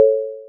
Free UI/UX sound effect: Message Sent.
Message Sent
030_message_sent.mp3